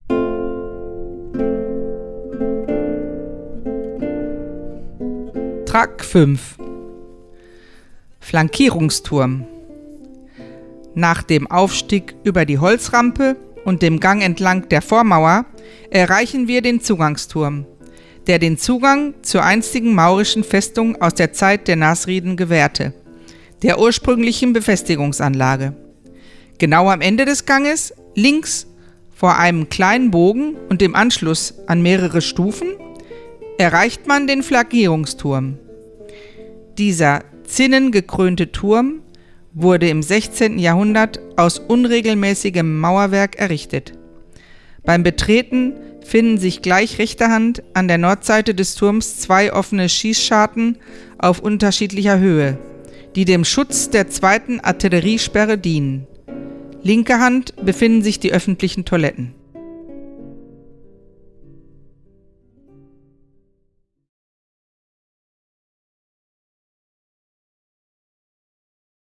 Burg von Salobreña, Besichtigung mit Audioguide